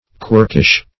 Search Result for " quirkish" : The Collaborative International Dictionary of English v.0.48: Quirkish \Quirk"ish\ (kw[~e]rk"[i^]sh), a. Consisting of quirks; resembling a quirk.